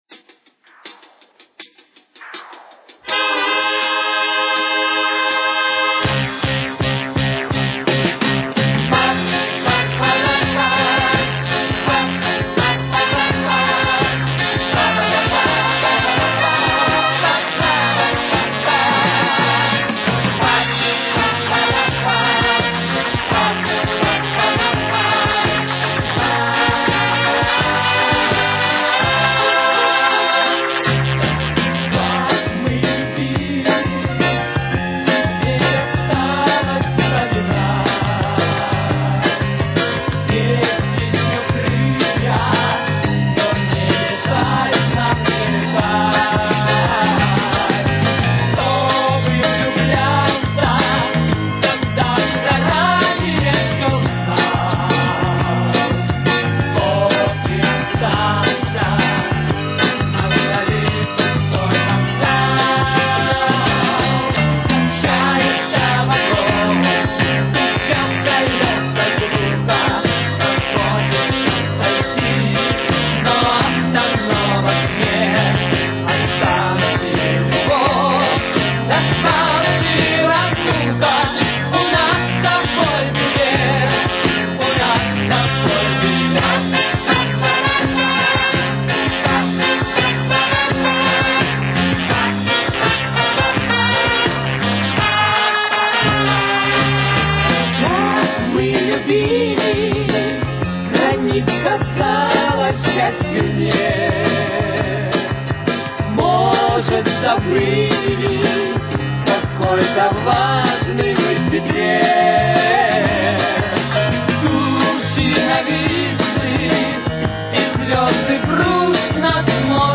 Тональность: Фа минор. Темп: 87.